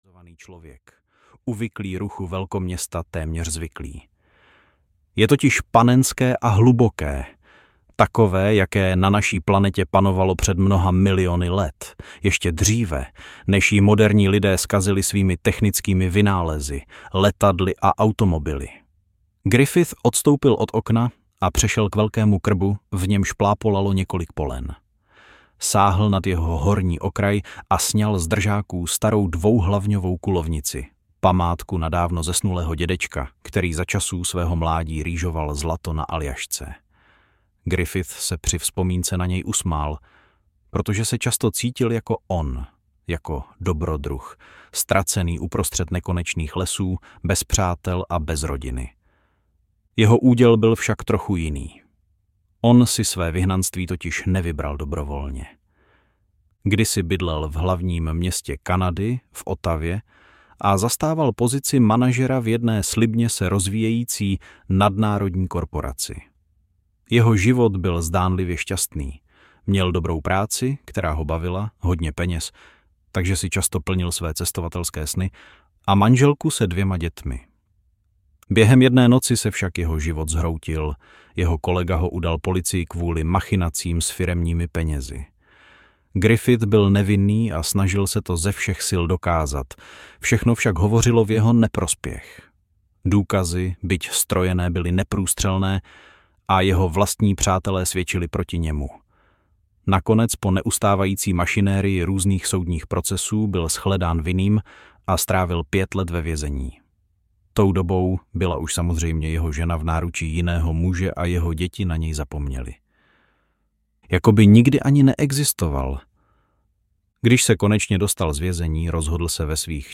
Erebus audiokniha
Ukázka z knihy